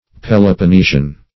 Peloponnesian \Pel`o*pon*ne"sian\, a. [L. Peloponnesius, fr.